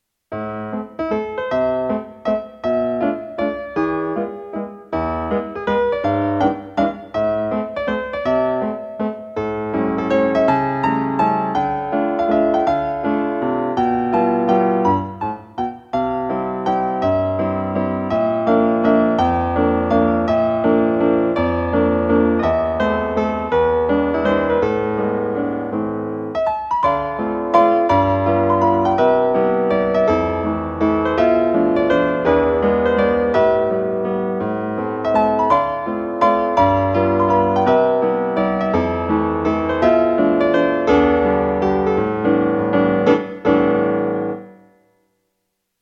♪ピアノ版